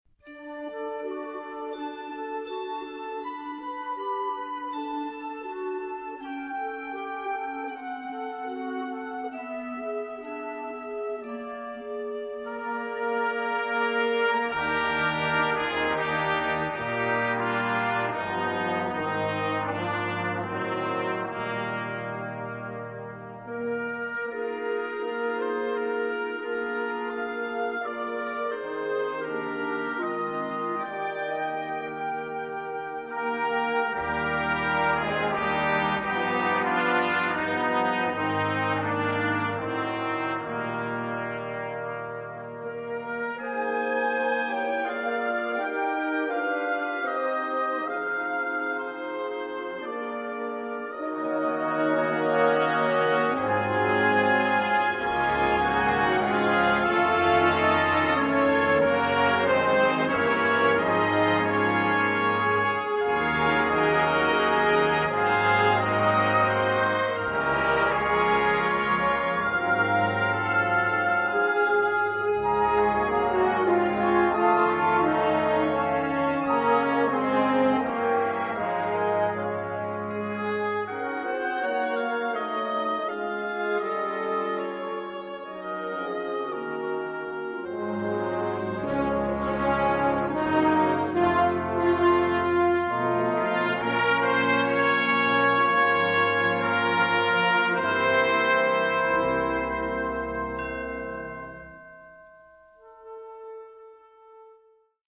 Lush and beautiful
Noten für Blasorchester.